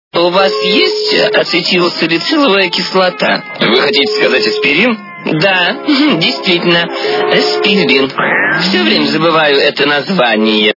» Звуки » Смешные » У вас есть ацетил-салициловая кислота? - Вы хотите сказать аспирин?
При прослушивании У вас есть ацетил-салициловая кислота? - Вы хотите сказать аспирин? качество понижено и присутствуют гудки.